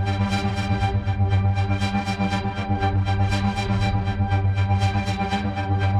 Index of /musicradar/dystopian-drone-samples/Tempo Loops/120bpm
DD_TempoDroneB_120-G.wav